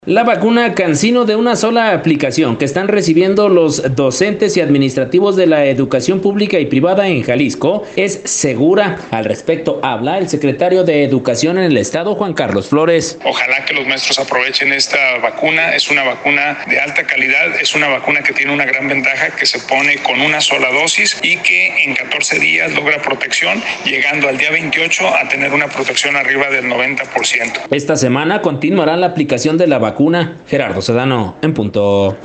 La vacuna Cansino de una sola aplicación que están recibiendo los docentes y administrativos de la educación pública y privada de Jalisco, es segura, al respecto habla el secretario de Educación en el Estado, Juan Carlos Flores: